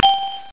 DING.mp3